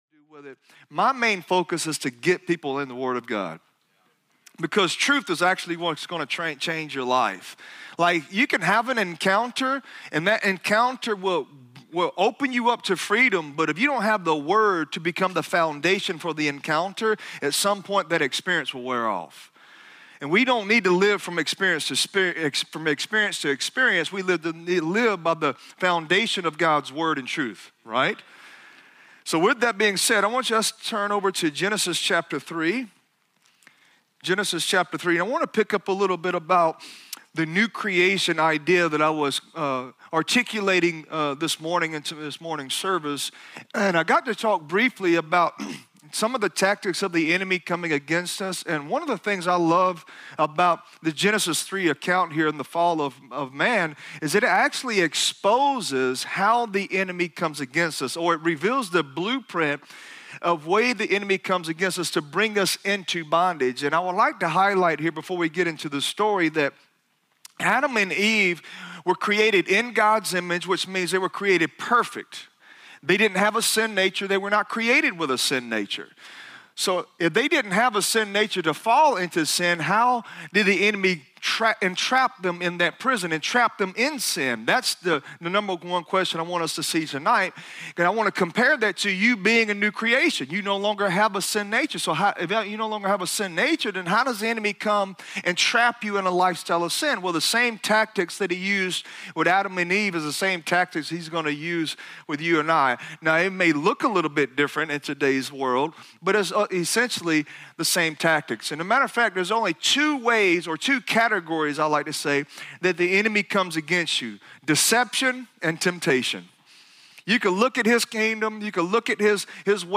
Sermons | Church of Grace
Guest Speaker